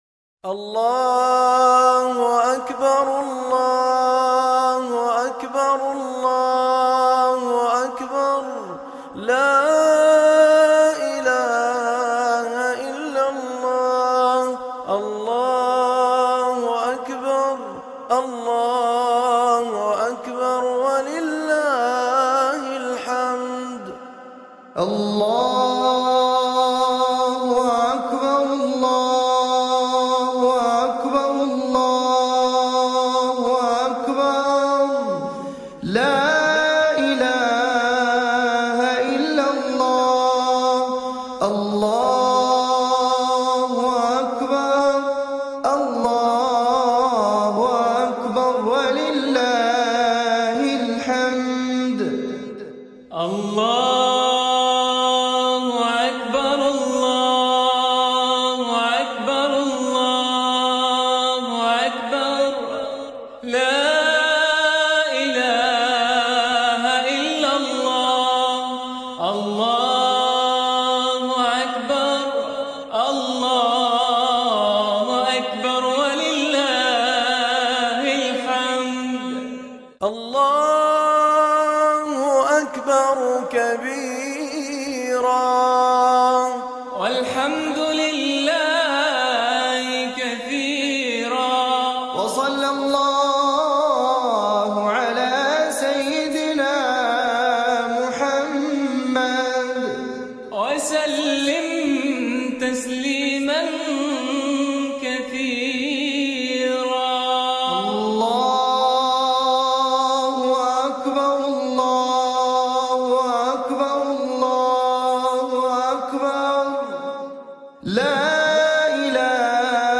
أجمل صيغ تكبيرات العيد 2026 المتاحة للتحميل
تكبيرات العيد "ساعة كاملة": مخصصة لتشغيلها في المحلات والبيوت لخلق أجواء احتفالية مستمرة.